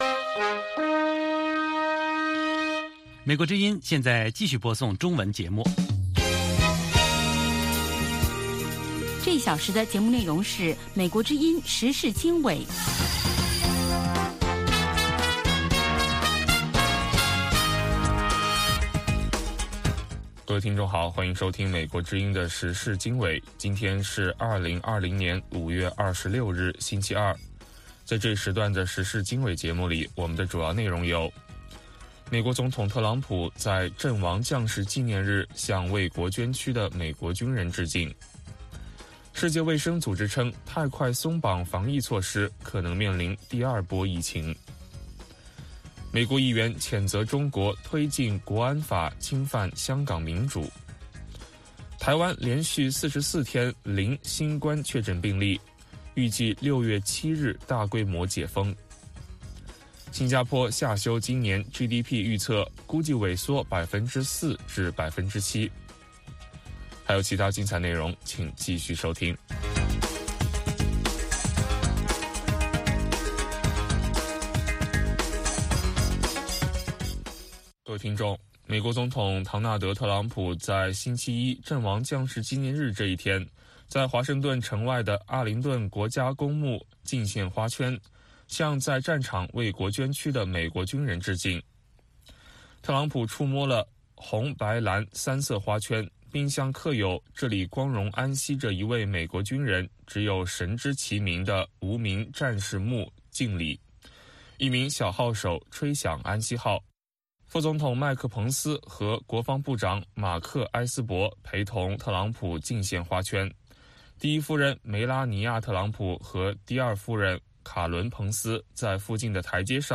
美国之音中文广播于北京时间每天晚上7-8点播出《时事经纬》节目。《时事经纬》重点报道美国、世界和中国、香港、台湾的新闻大事，内容包括美国之音驻世界各地记者的报道，其中有中文部记者和特约记者的采访报道，背景报道、世界报章杂志文章介绍以及新闻评论等等。